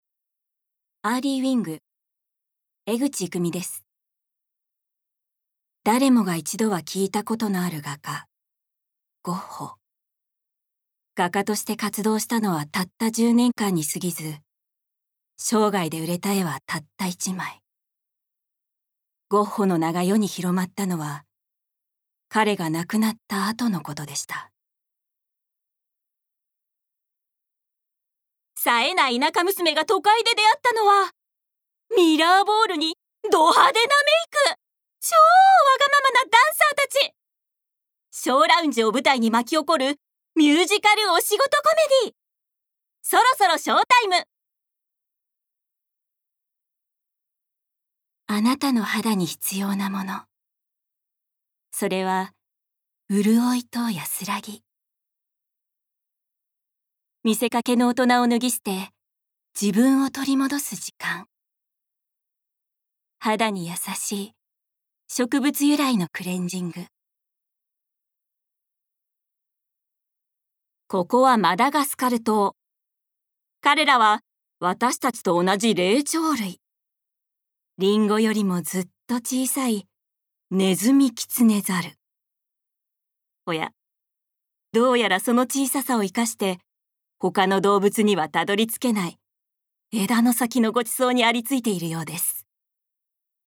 ボイスサンプル
ナレーションALL